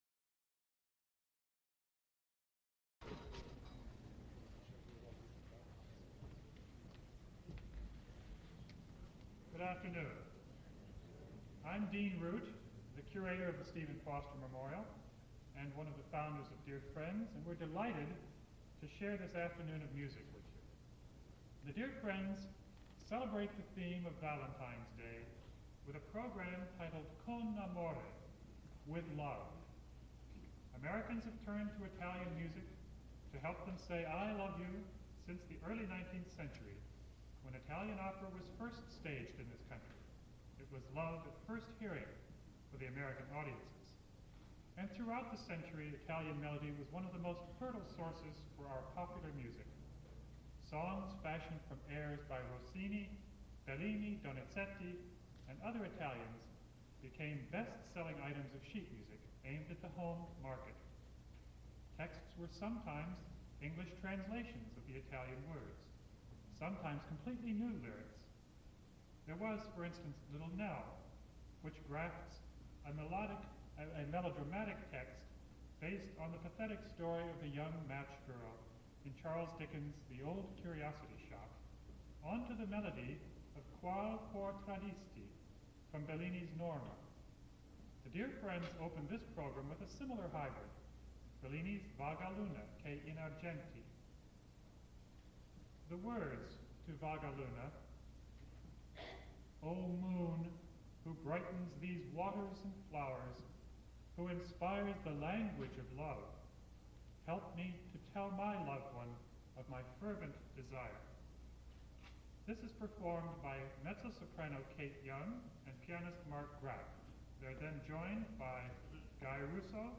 Spoken intro for Con Amore Italian Music in 19th Century America concert